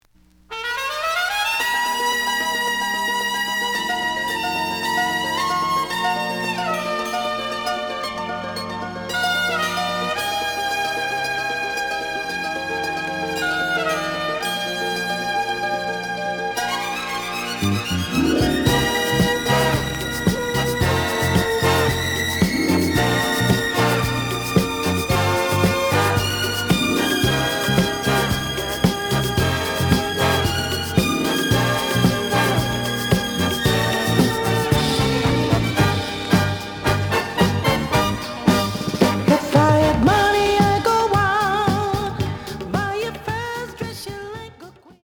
試聴は実際のレコードから録音しています。
●Format: 7 inch
●Genre: Soul, 70's Soul